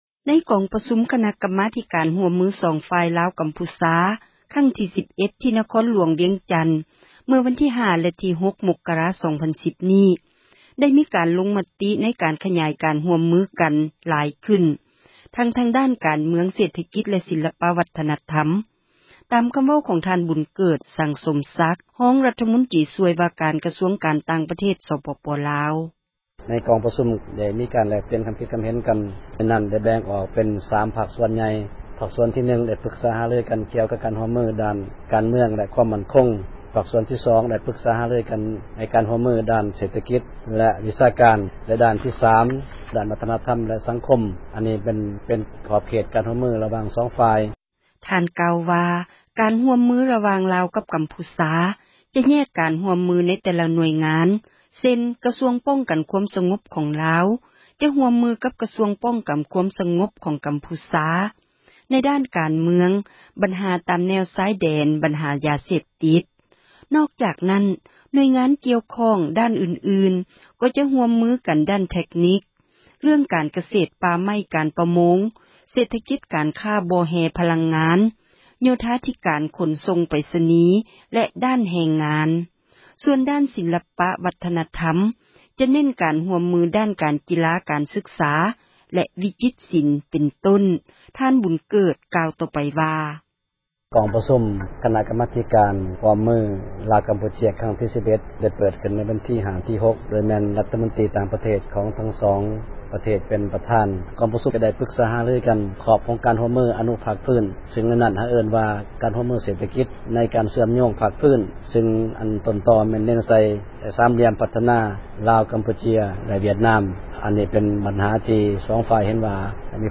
ໃນກອງປະຊຸມ ຄະນະກັມມາທິການ ຮ່ວມມື 2 ຝ່າຍ ລາວ-ກັມພູຊາ ຄັ້ງທີ 11 ທີ່ນະຄອນຫລວງວຽງຈັນ ເມື່ອວັນທີ 5 ແລະ 6 ມົກກະຣາ 2010 ນີ້ ໄດ້ມີການລົງມະຕິ ໃນການຂຍາຍ ການຮ່ວມມືກັນຫລາຍຂຶ້ນ ທັງທາງດ້ານການເມືອງ ເສຖກິດ ແລະ ສີລປະວັທນະທັມ ຕາມຄໍາເວົ້າຂອງ ທ່ານບູນເກີດ ສັງສົມສັກ ຮອງຣັຖມົນຕຣີ ຊ່ວຍວ່າການ ກະຊວງການຕ່າງປະເທດ ສປປລາວ: